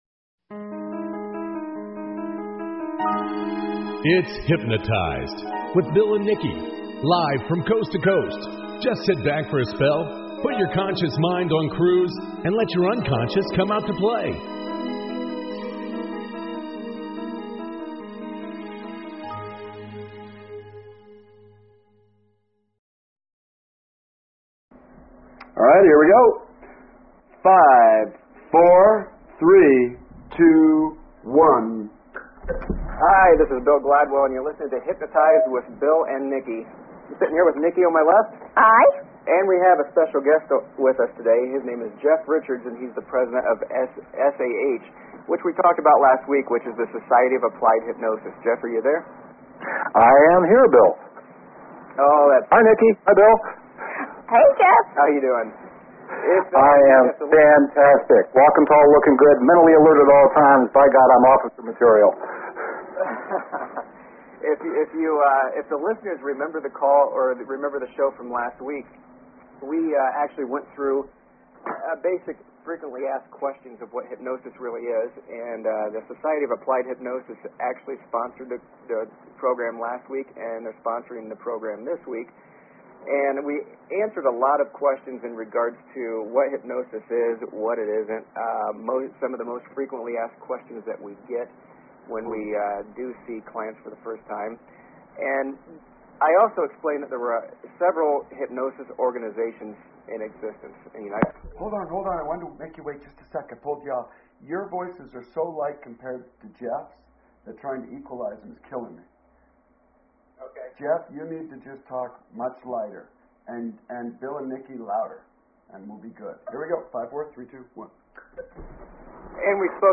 Talk Show Episode, Audio Podcast, Hypnotized and Courtesy of BBS Radio on , show guests , about , categorized as